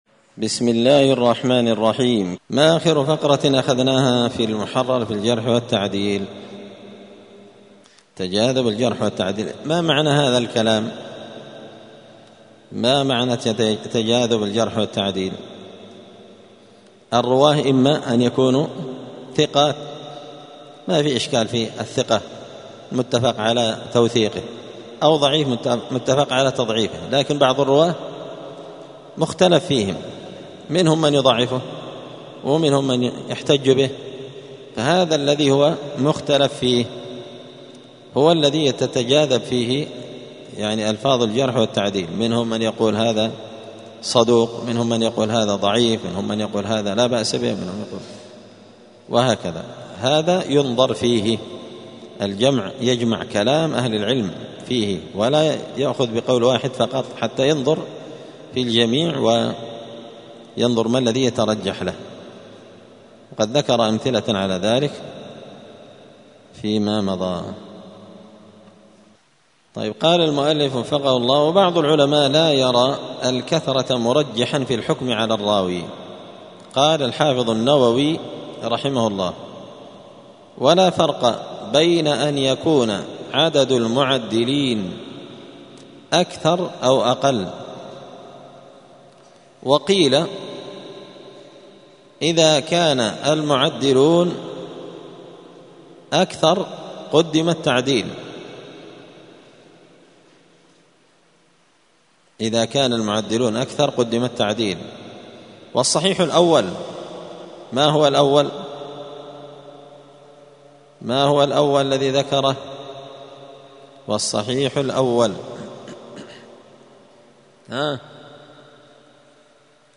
*الدرس الرابع والخمسون (54) تابع لباب تجاذب الجرح والتعديل.*